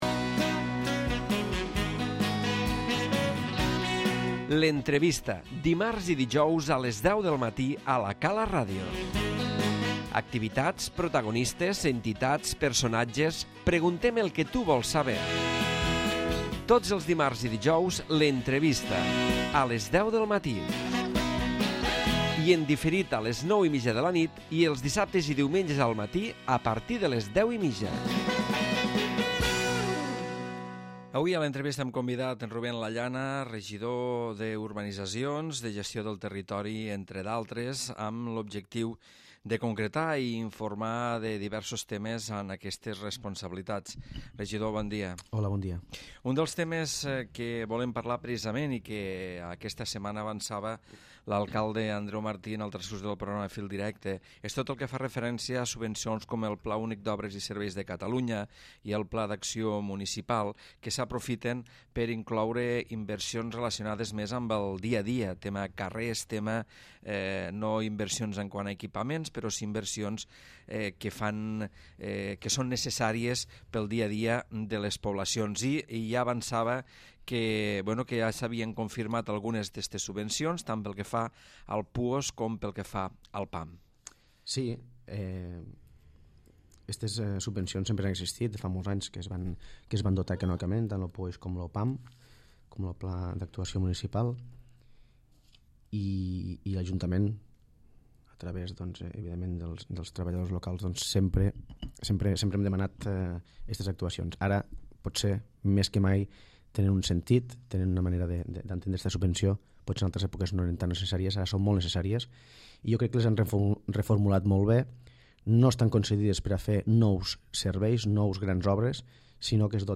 L'Entrevista
Ruben Lallana, regidor de gestió del Territori i d'Urbanitzacions ha parlat a l'entrevista sobre les subvencions del PUOSC i el PAM, i també de la implantació del wimax a les urbanitzacions, del centre Social Calaforn de Tres Cales i de la nova pàgina web d'informació a les urbanitzacions.